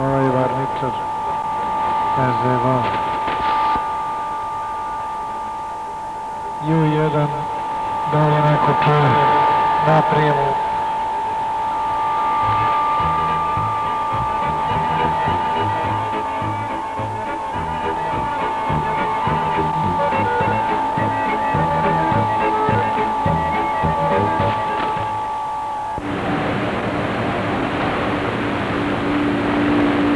Pirate radio from Serbia received in Finland
Serbian pirates on Medium Wave
They are mostly QSO-stations with no music programmes.